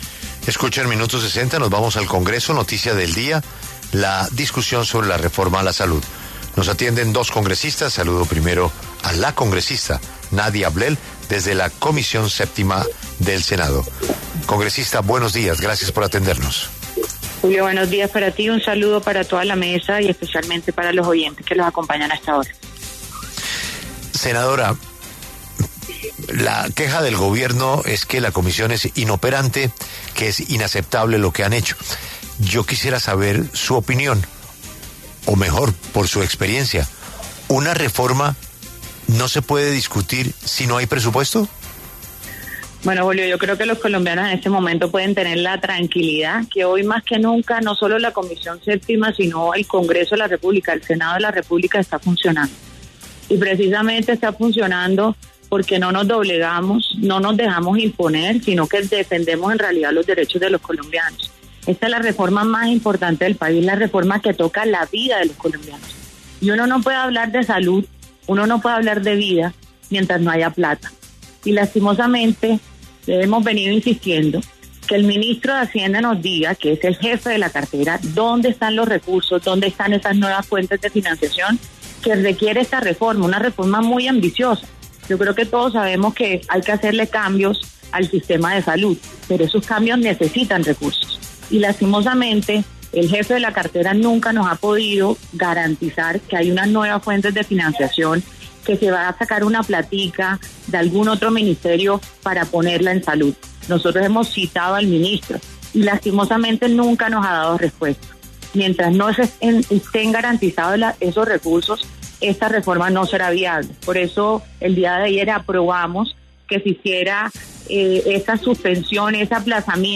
Los senadores de la Comisión Séptima del Senado, Nadia Blel, del Partido Conservador, y Fabian Díaz Plata, de la Alianza Verde, pasaron por los micrófonos de La W.